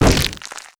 SPLAT_Generic_05_mono.wav